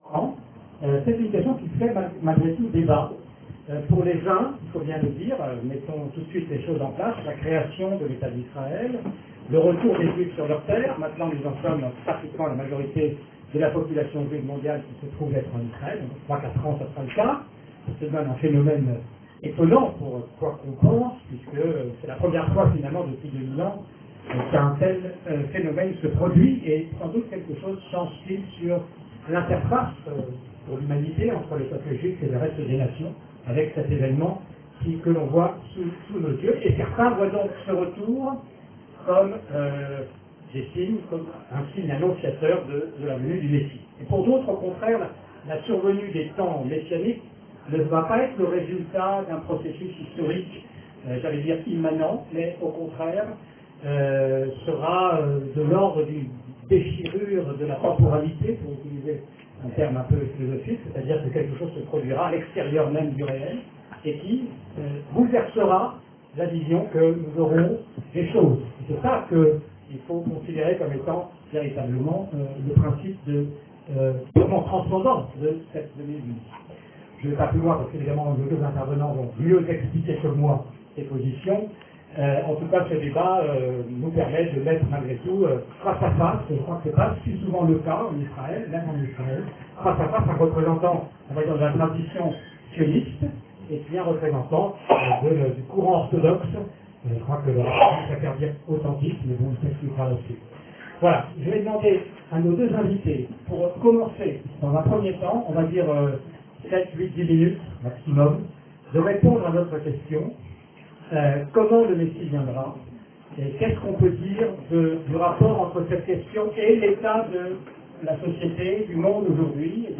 Machiah Tel Aviv Identite שיעור מ 23 מרץ 2020 01H 13MIN הורדה בקובץ אודיו MP3 (13.54 Mo) הורדה בקובץ אודיו M4A (8.64 Mo) TAGS : Etude sur la Gueoula Emouna Moussar Torah et identite d'Israel שיעורים קצרים